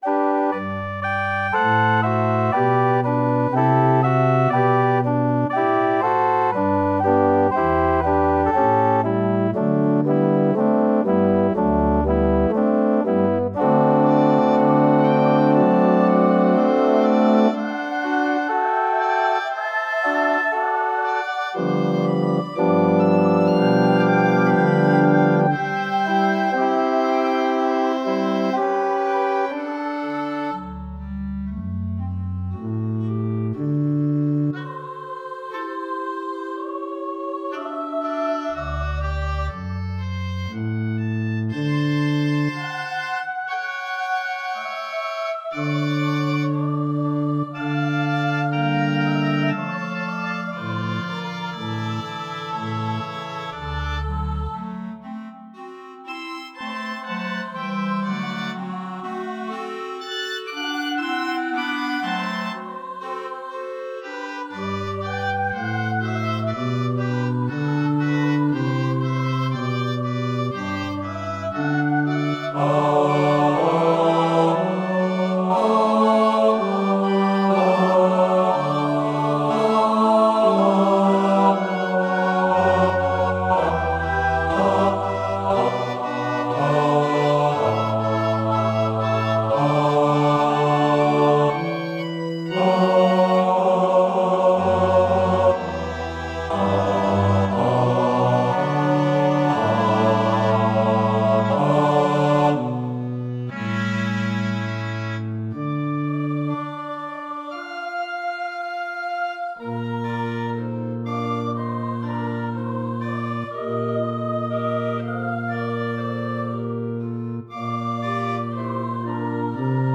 Mp3 files are provided for each movement, with each voice part emphasised, or with all voice parts at the same level.